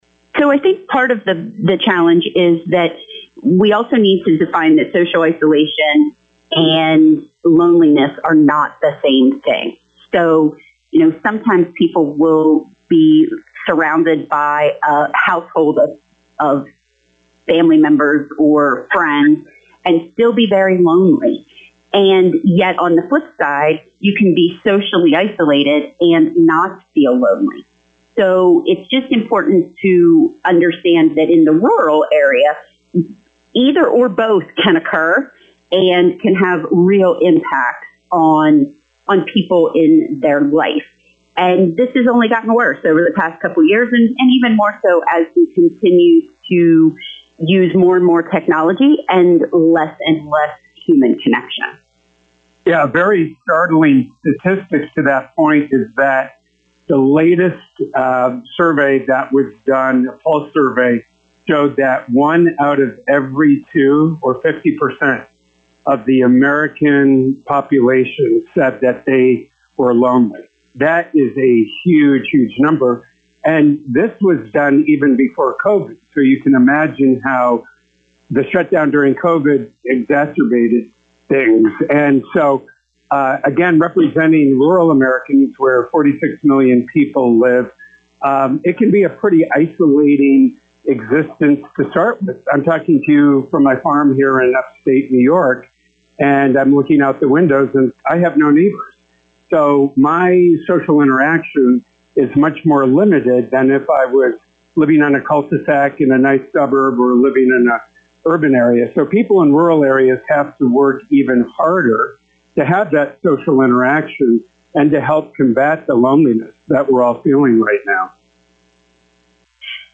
AI Generated summary of the interview: